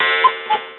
cuckoo.mp3